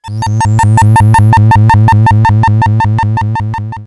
UFO10.wav